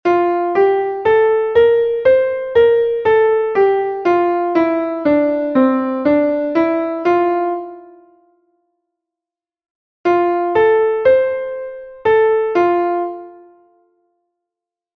Entoación a capella
Melodía en 6/8 en Fa M
Escala e arpexios:
escala_arpegio_fa_maior.mp3